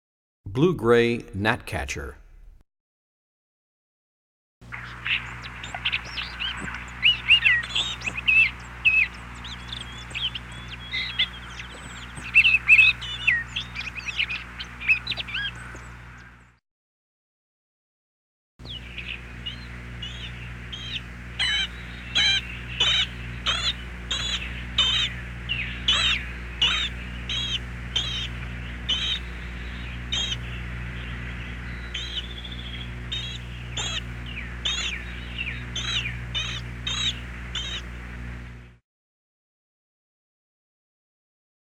14 Blue Gray Gnatcatcher.mp3